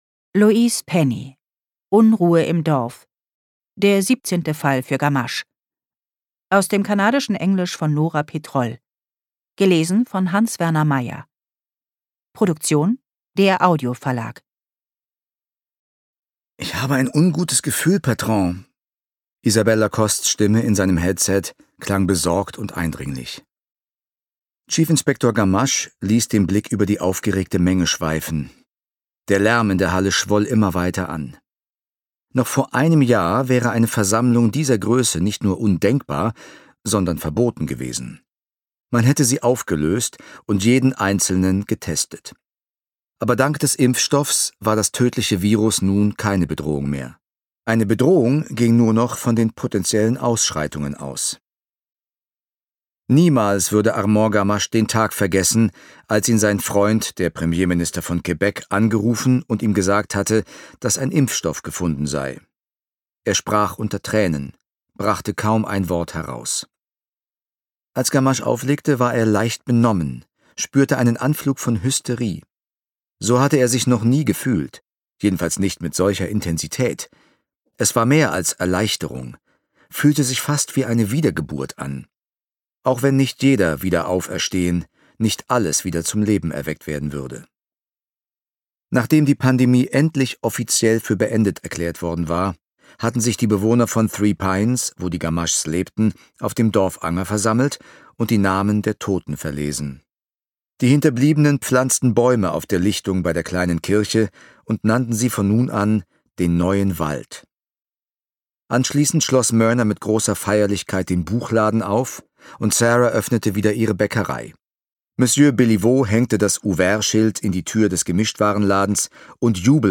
Ungekürzte Lesung mit Hans-Werner Meyer (2 mp3-CDs)
Hans-Werner Meyer (Sprecher)